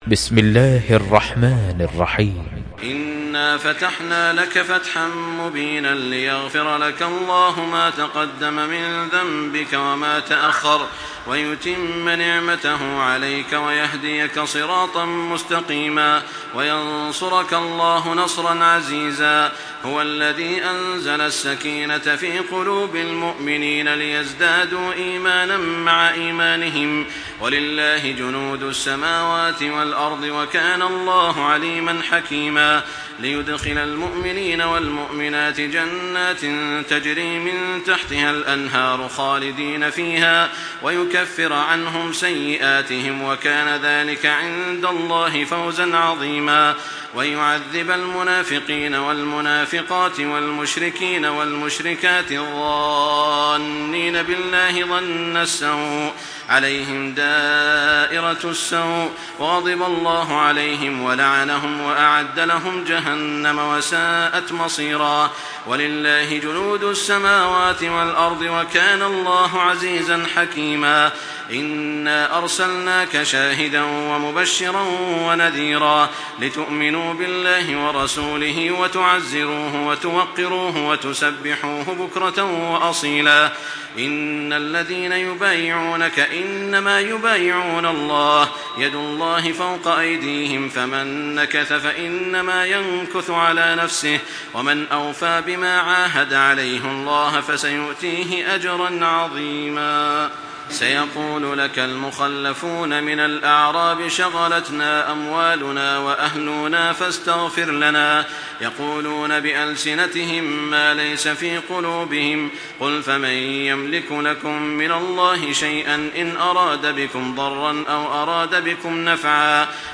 Surah الفتح MP3 by تراويح الحرم المكي 1425 in حفص عن عاصم narration.